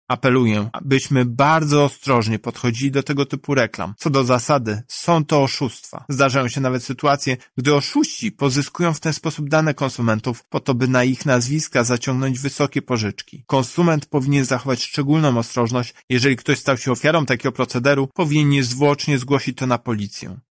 Szansa na zarobek w takich przedsięwzięciach jest niewielka – tłumaczy Tomasz Chróstny Prezes UOKiK: